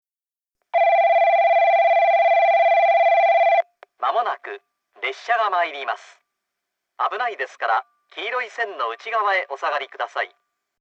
1番のりば接近放送　男声
放送は九州カンノ型Bです。
スピーカーは1番のりばがTOAの灰色のラッパ型、2番のりばがカンノボックス型でしたが、現在は1番のりばはUNI-PEXクリアホーン、2番のりばはソノコラム（ミニ）に取り換えられています。